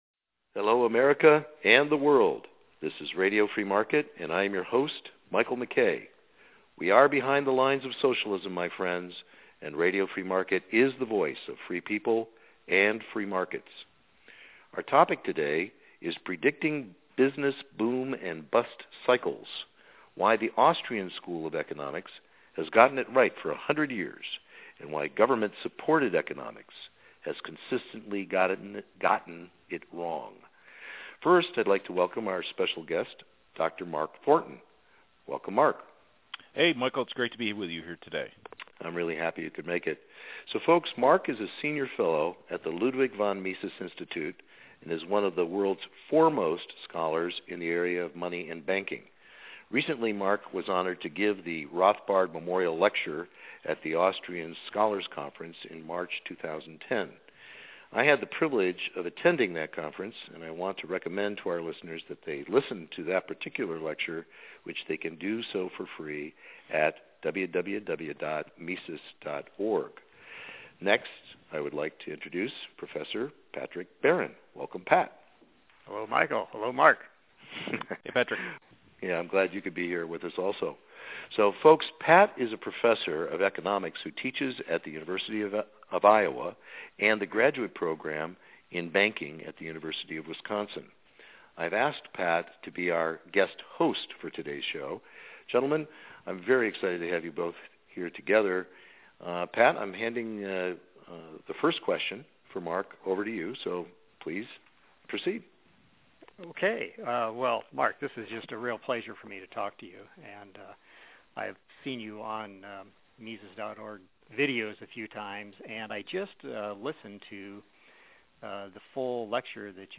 In this lively conversation listeners will learn Why Does Austrian Economics Successfully Predict Booms and Busts and What Those in Charge in Washington are Missing .